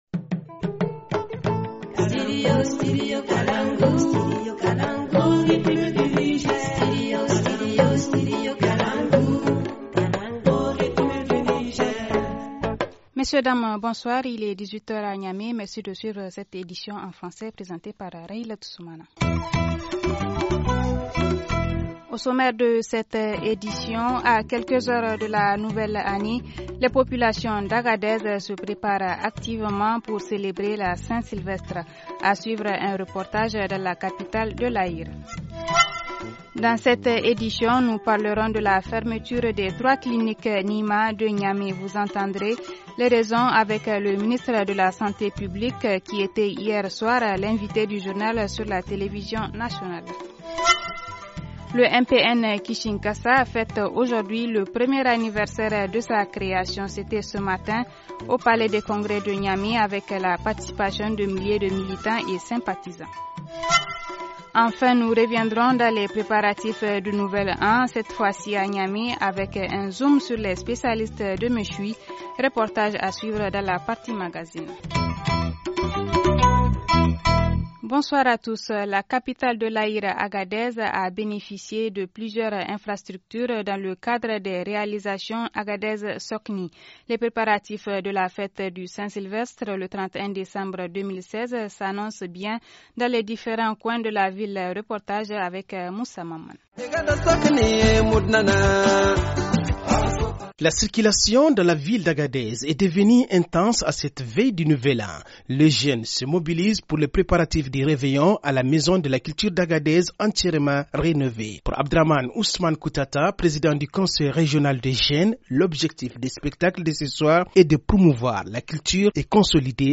1. A quelques heures de la nouvelle année, les populations d’Agadez se préparent activement pour célébrer la Saint Sylvestre. A suivre un reportage dans la capitale de l’Aïr.
2. Dans cette édition nous parlerons de la fermeture des 3 cliniques NIIMA de Niamey. Vous entendrez les raisons avec le ministre de la Santé publique qui était hier soir, l’invité du journal sur la télévision nationale.